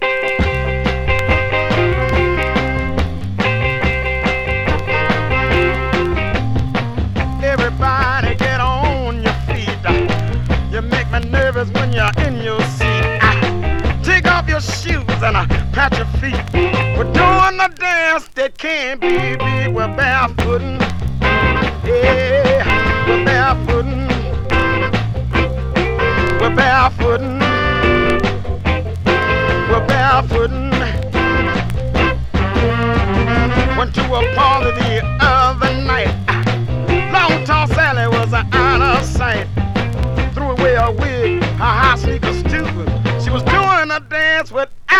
Rhythm & Blues, Funk, Soul　USA　12inchレコード　33rpm　Mono